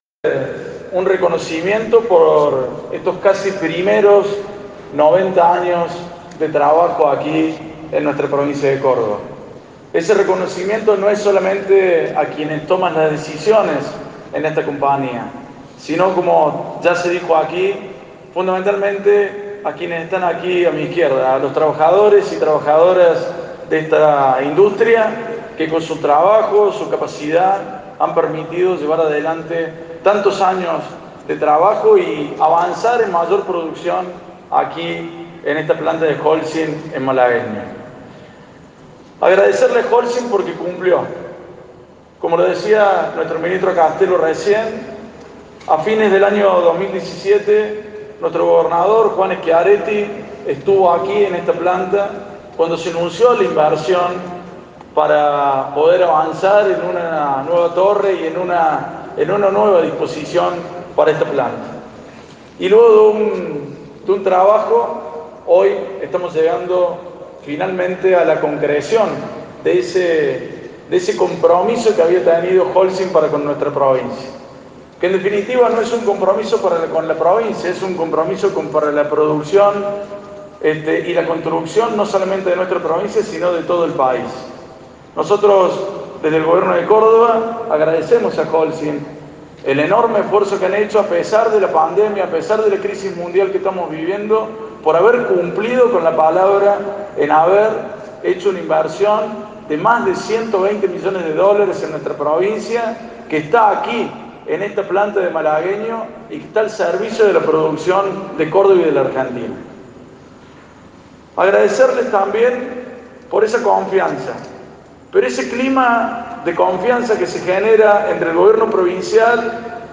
Audio: Manuel Calvo (Vice gobernador de Córdoba).